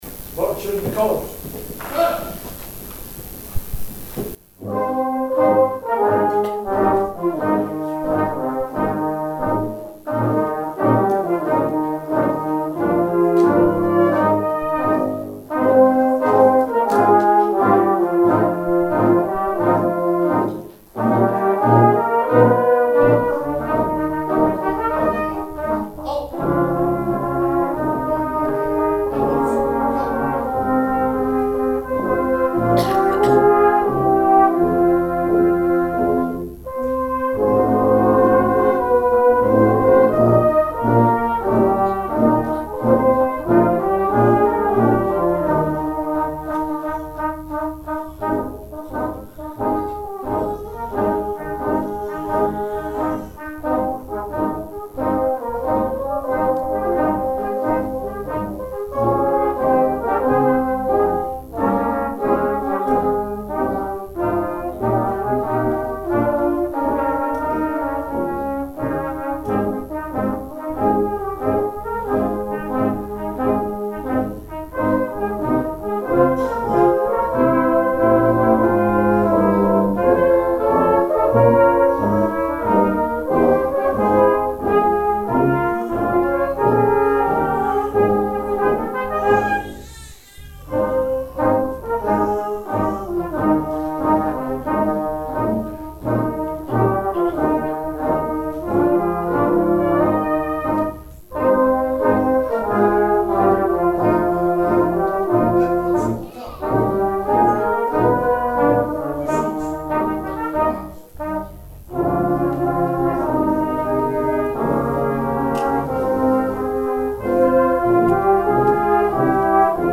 approached to the tune of Boys of the Old Brigade, played by the Penicuik Silver Band.